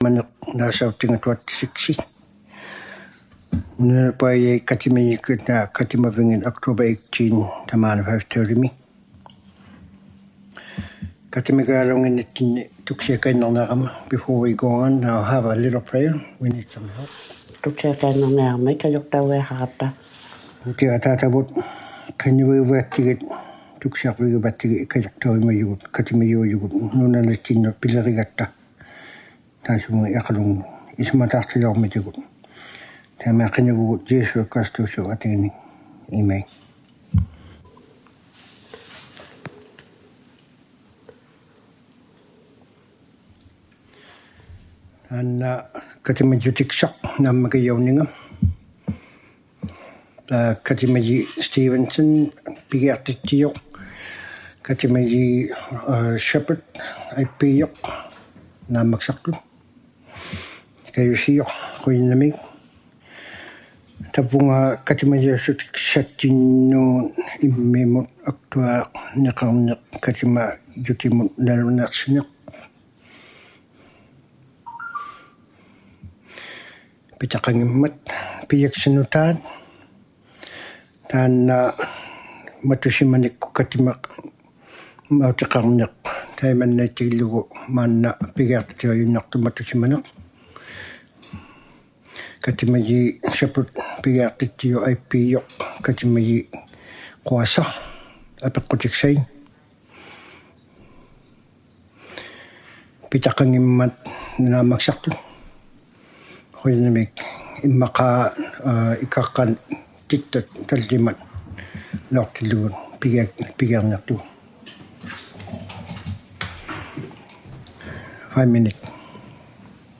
ᓄᓇᓕᐸᐅᔭᒃᑯᑦ ᐃᖃᓗᐃᑦ ᑐᐊᕕᕐᓇᑐᒃᑯᑦ ᑲᑎᒪᓂᖅ #26 Emergency City Council Meeting #26 | City of Iqaluit